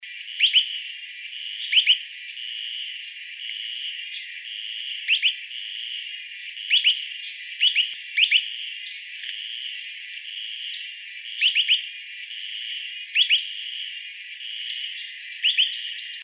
Picochato Enano (Platyrinchus mystaceus)
Nombre en inglés: White-throated Spadebill
Localidad o área protegida: Bio Reserva Karadya
Condición: Silvestre
Certeza: Vocalización Grabada